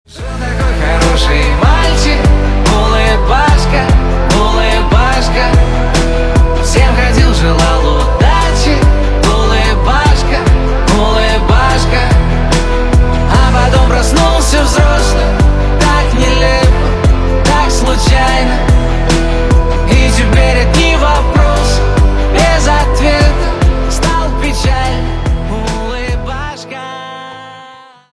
# # 2023 # Поп Музыка # Русские